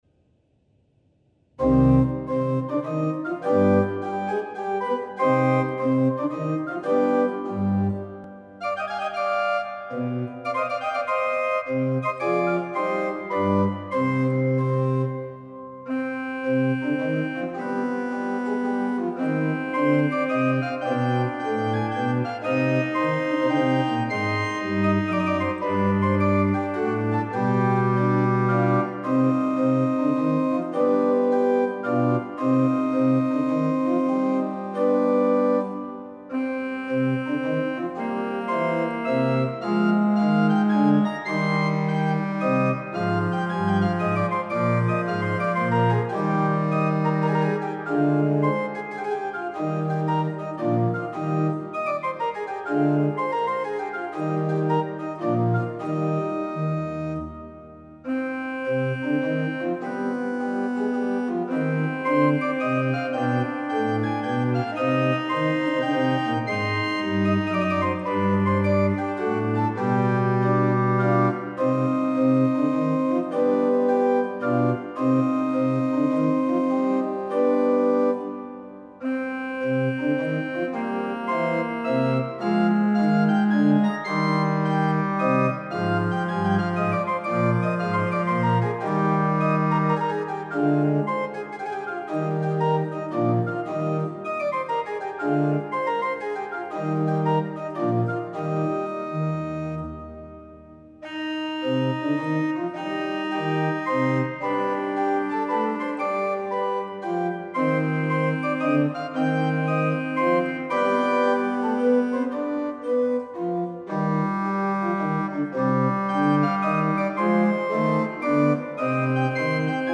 The recording was done the sampleset, made by Sonus Paradis, of the Transept organ in the Laurenskerk, Rotterdam.